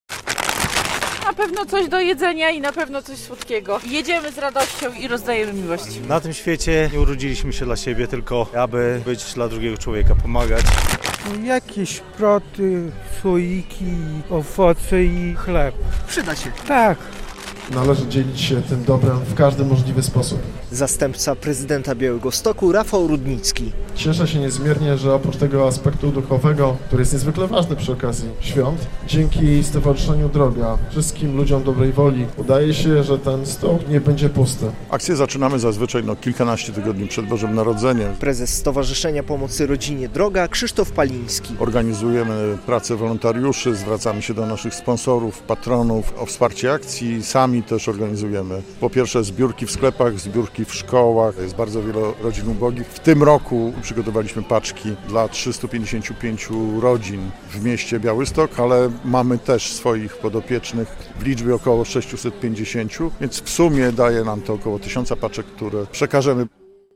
Radio Białystok | Wiadomości | Wiadomości - Świąteczna pomoc dla potrzebujących - Stowarzyszenie "Droga" rozdaje paczki z żywnością